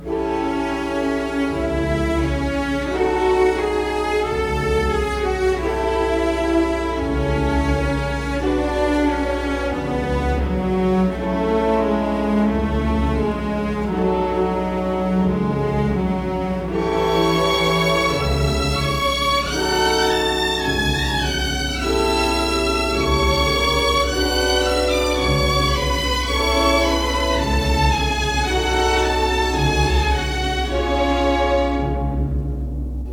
1959 stereo recording